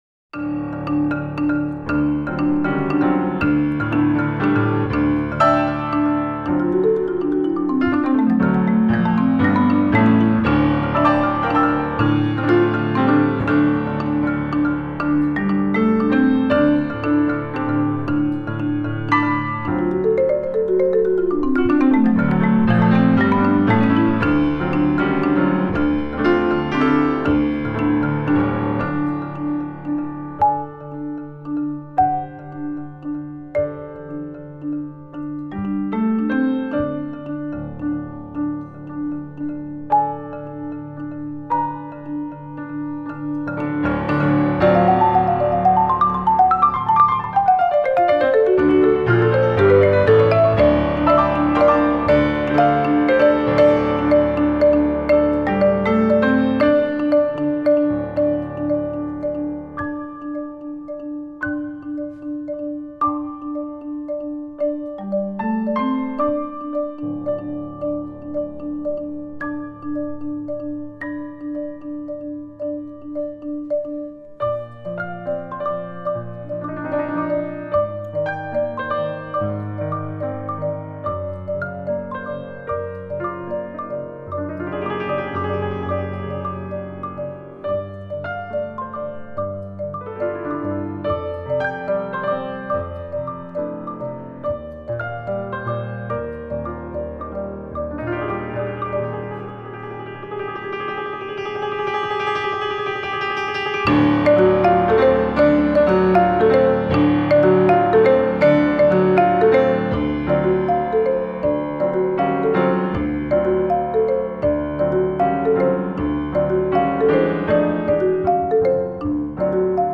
duet for marimba and piano